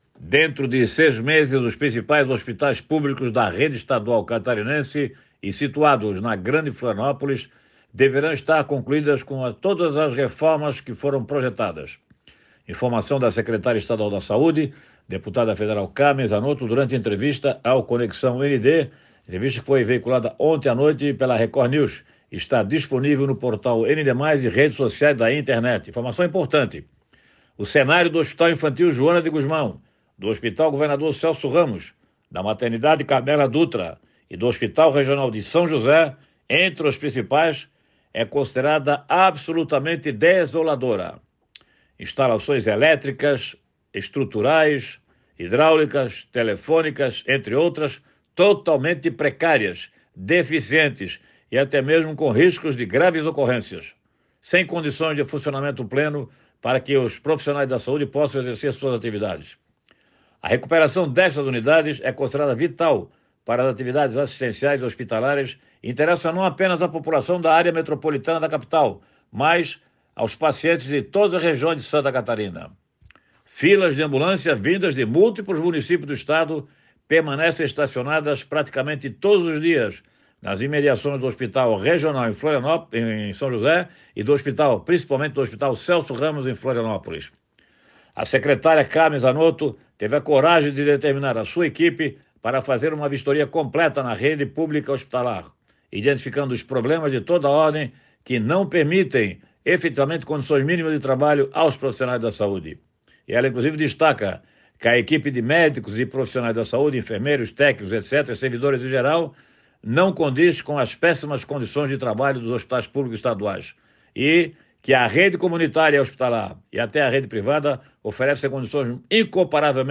Confira o comentário na íntegra